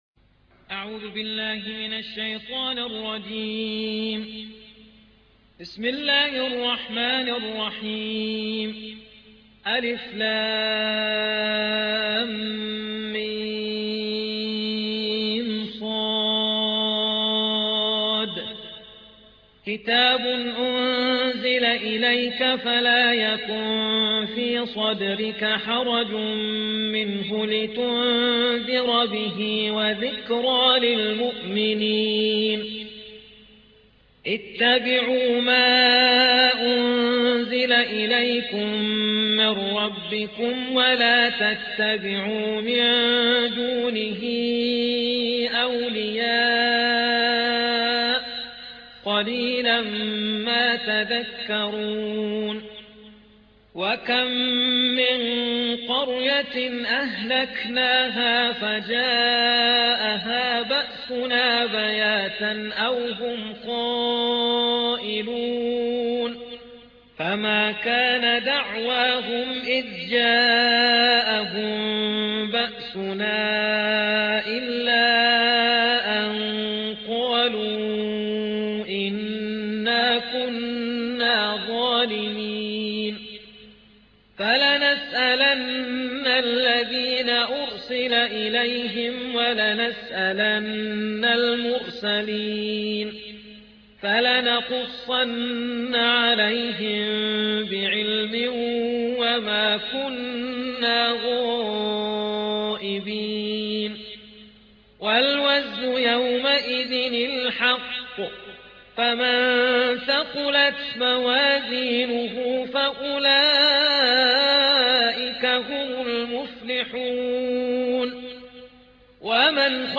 Écoutez et téléchargez le Saint Coran en ligne récité par différents récitateurs.